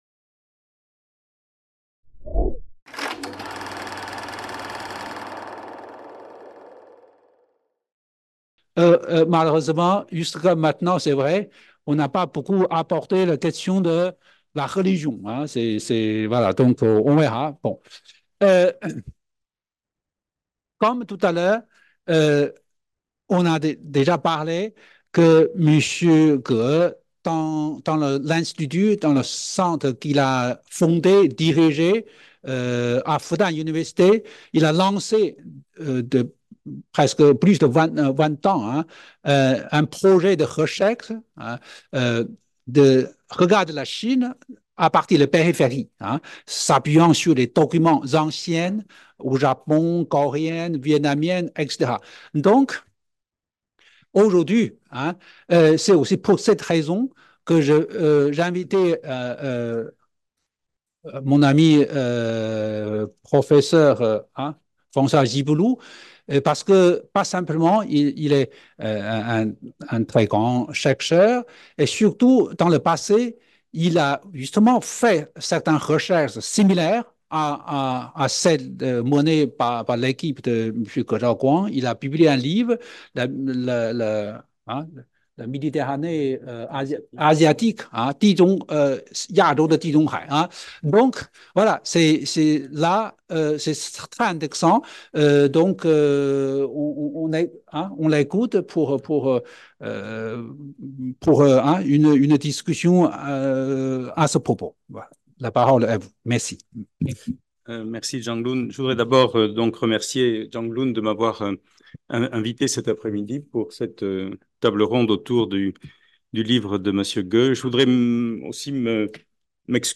Deuxième partie de l'après-midi de la table ronde autour de l'ouvrage "Qu'est ce que la Chine ?" de Zhaoguang Ge | Canal U